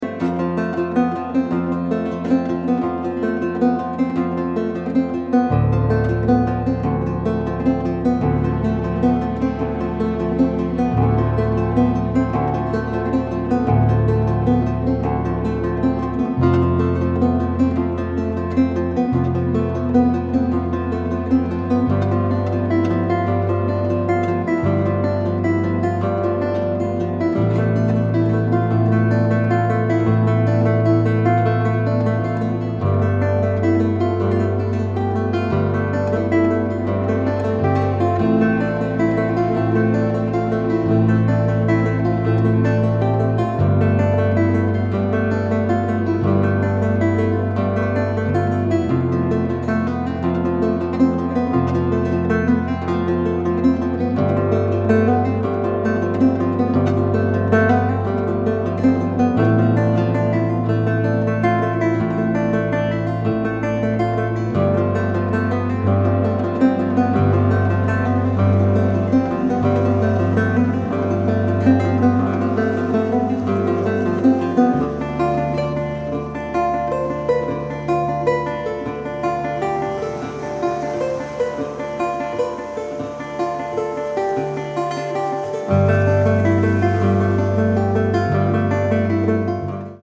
Contemporary
Lute